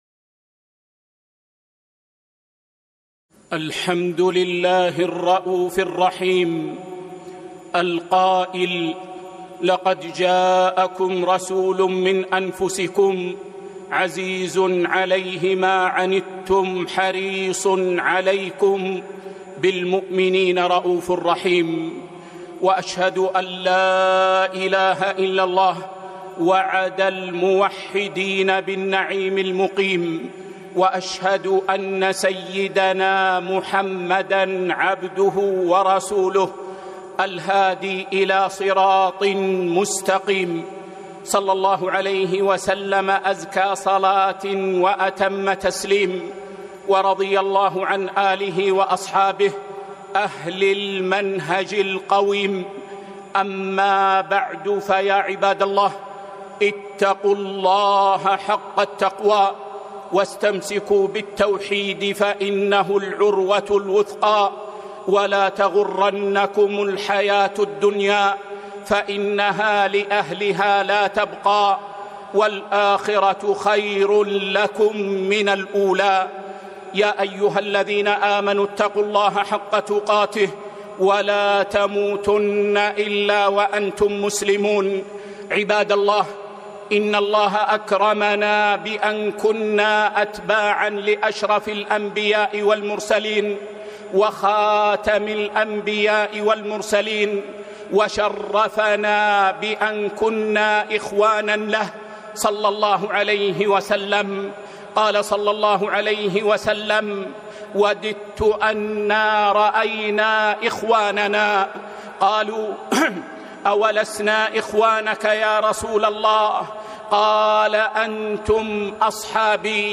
خطبة - إنه رسول الله